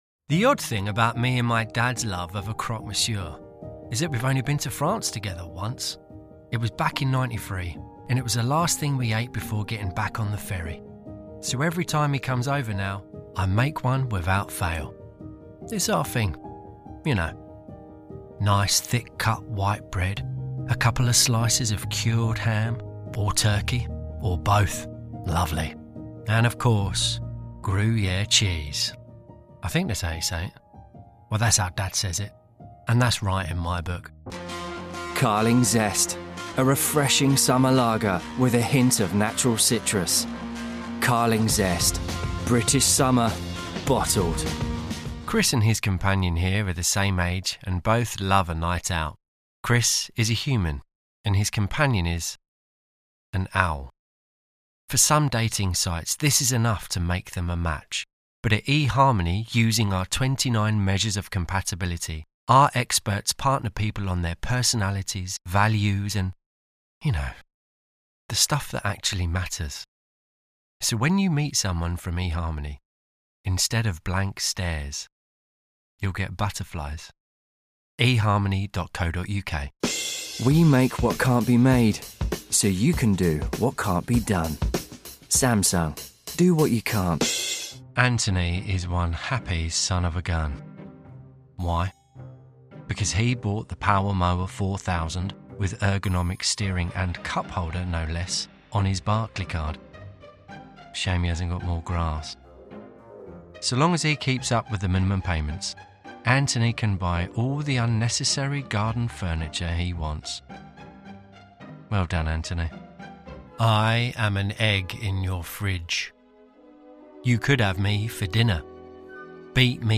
English (British)
Relatable
Sincere
Funny